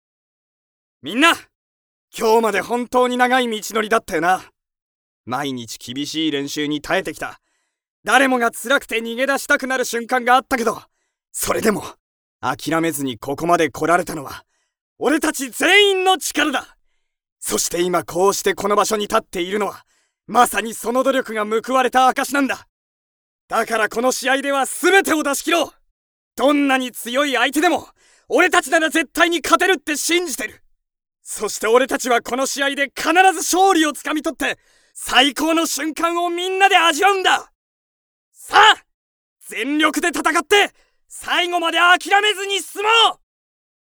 次に感情を込めて読んでみます。
いよいよ始まるという緊張感や高揚感、仲間を鼓舞する気持ちが乗ったセリフになる必要がありますが、この短いセリフの中で気持ちの高まりがあるはずなので、気持ちのピークはどこに来るのかを考えて、最初から最後まで声を張り上げるだけにならないよう気を付けています。
【感情をこめたセリフ】